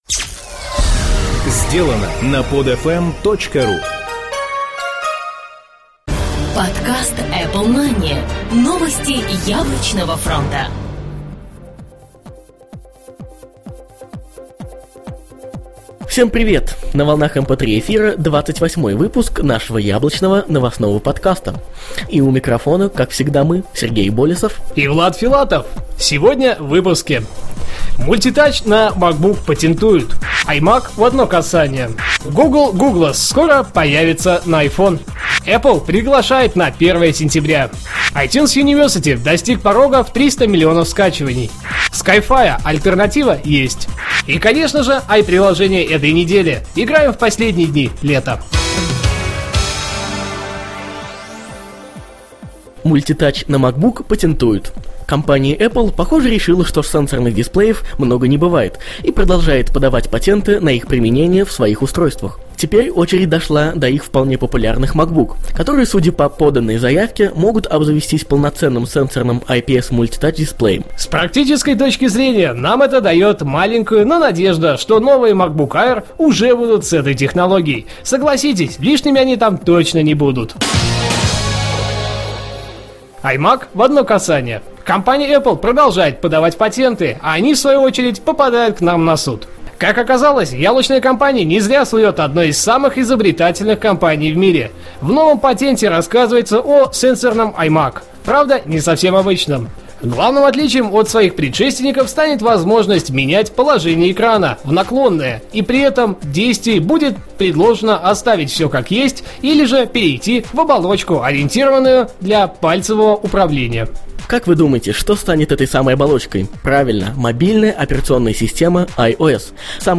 Жанр: новостной Apple-podcast